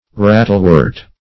Meaning of rattlewort. rattlewort synonyms, pronunciation, spelling and more from Free Dictionary.
Search Result for " rattlewort" : The Collaborative International Dictionary of English v.0.48: Rattlewort \Rat"tle*wort`\ (-w[^u]rt`), n. [AS. hr[ae]telwyrt.]